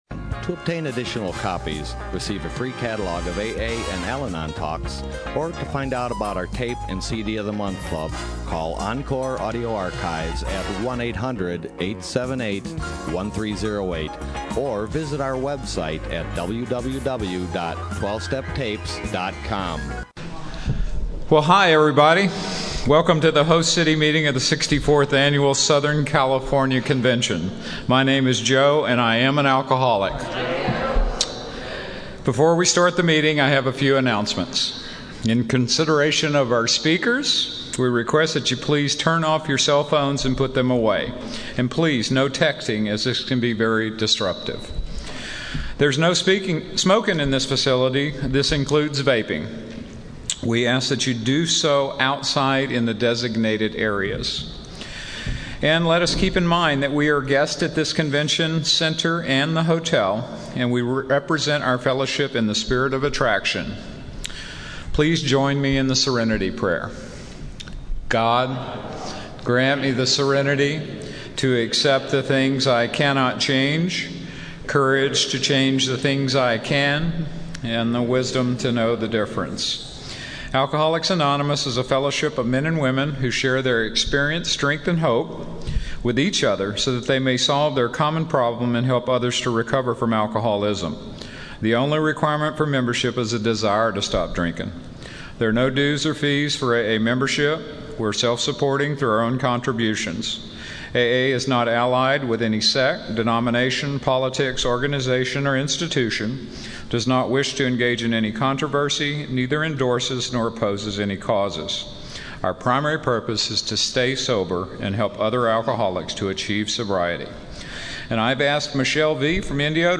SoCAL AA Convention